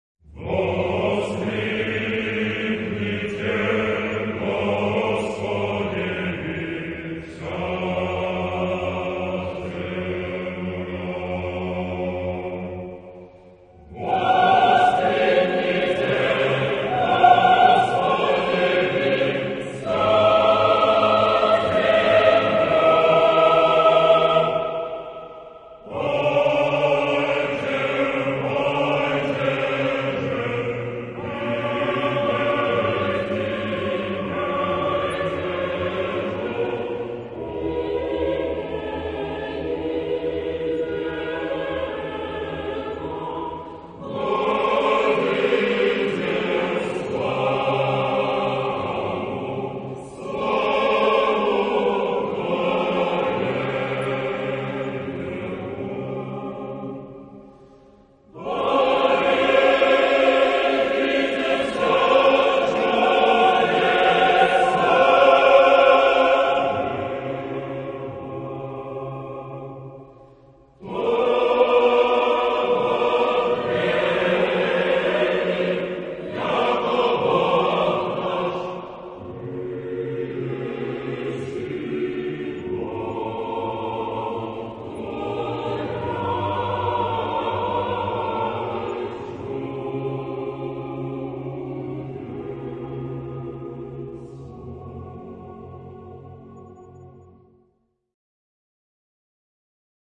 Sacred ; Orthodox liturgical hymn ; Orthodox song ; Liturgy ; Psalm ; Sacred concerto
festive ; majestic ; prayerful
SSAATTBB (8 voices )
F sharp major